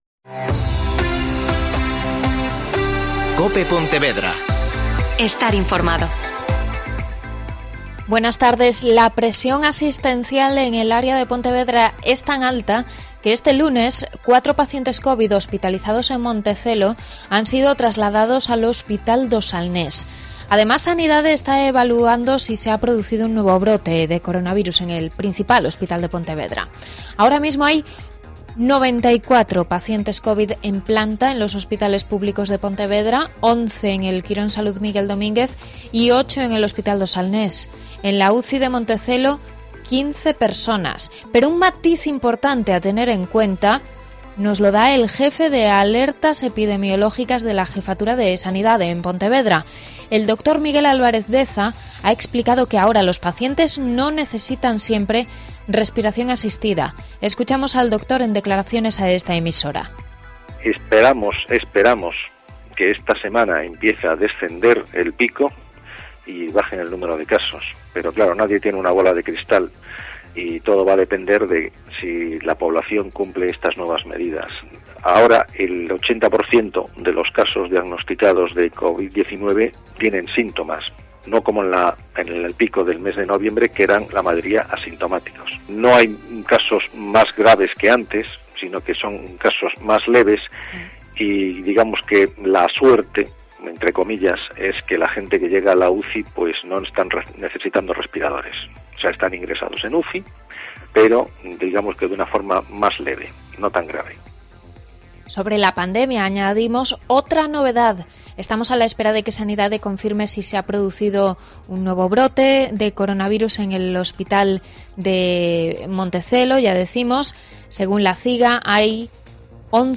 Mediodía COPE Pontevedra (Informativo 14:20)